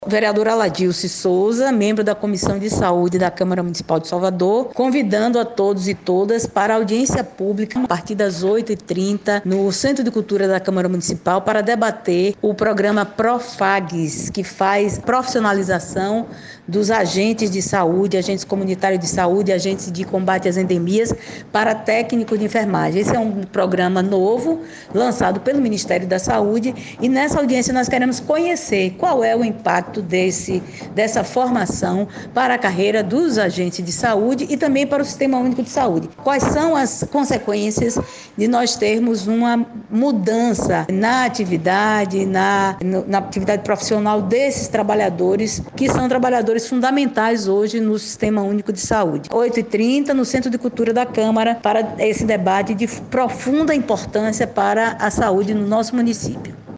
áudio da vereadora Aladilce Souza:
Vereadora-Aladilce-Souza-membro-da-comissão-de-saude-camara-municipal-ssa.mp3